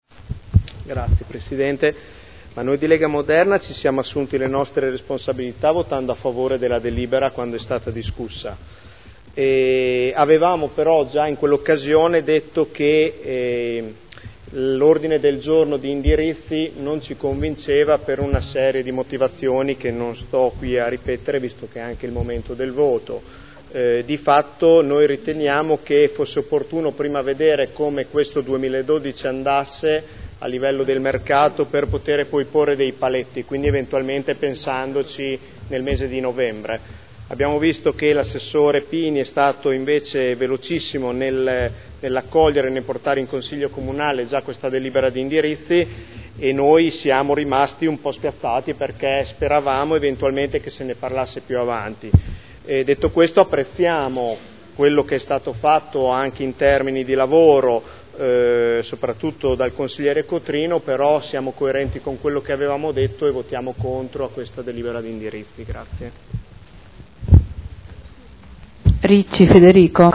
Nicola Rossi — Sito Audio Consiglio Comunale
Seduta del 30 gennaio PROPOSTE DI DELIBERAZIONE Documento di indirizzo per gli eventi di antiquariato della città di Modena Dichiarazioni di voto